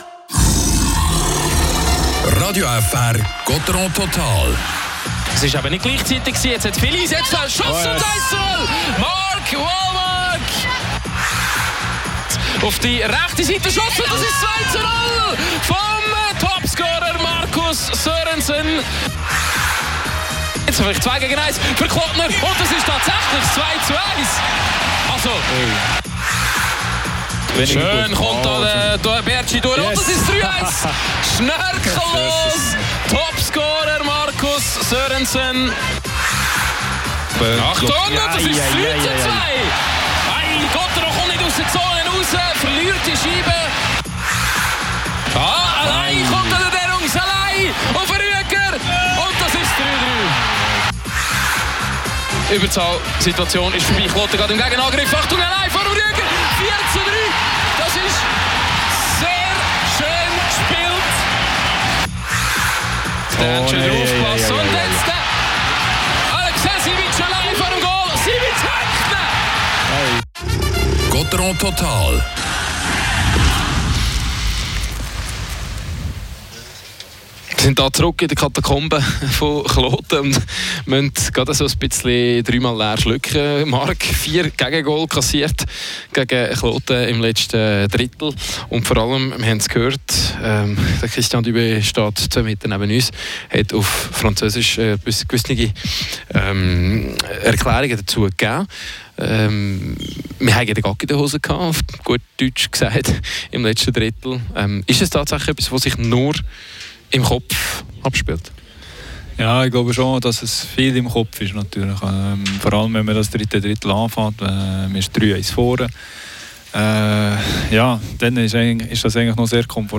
Gottéron unterliegt Auswärts dem EHC Kloten mit 5:3. Spielanalyse